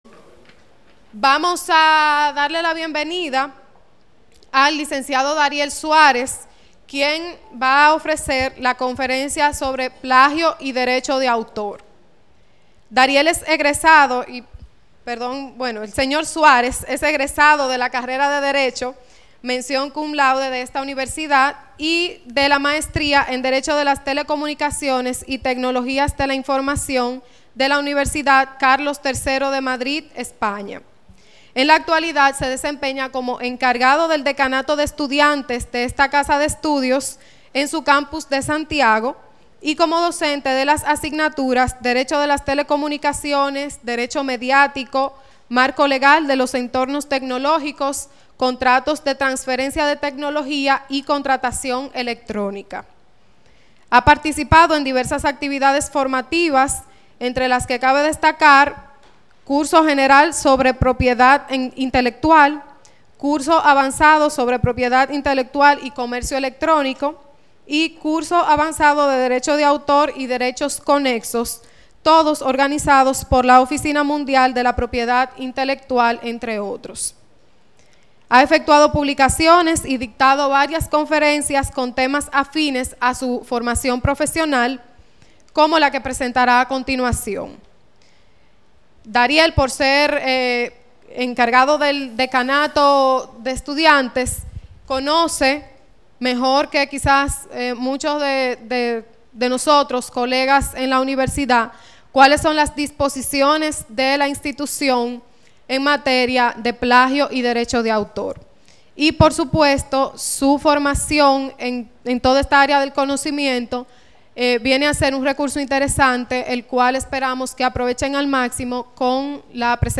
Dirigida a los estudiantes de postgrado que están en el proceso de elaboración de sus tesis, 8 de octubre, 2009, PUCMM, Recinto Santo Tomás de Aquino.
conferenciaAO2.mp3